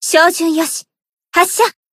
贡献 ） 分类:蔚蓝档案语音 协议:Copyright 您不可以覆盖此文件。
BA_V_Tomoe_Battle_Shout_1.ogg